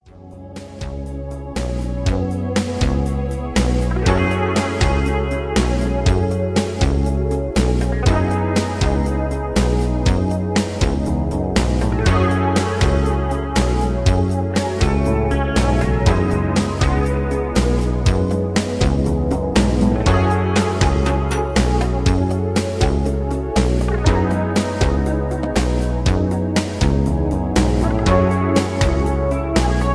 Background music suitable for TV/Film use.